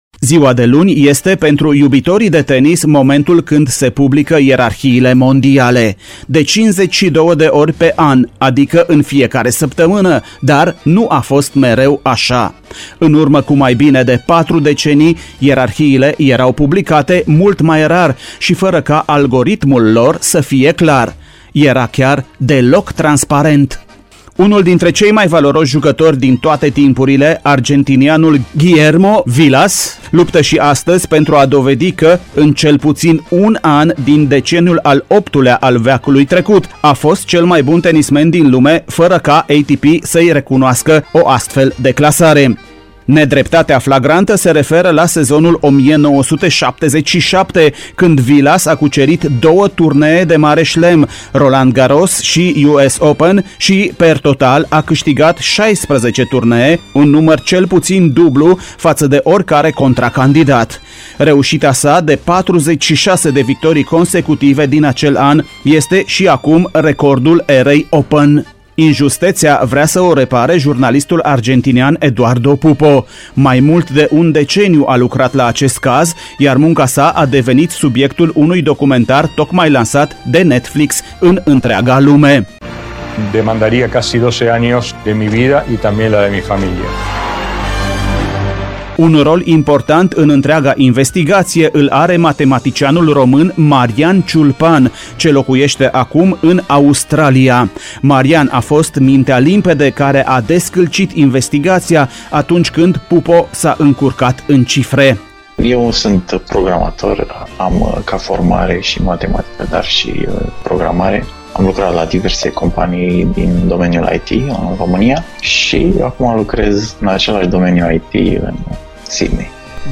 Reportajul AUDIO complet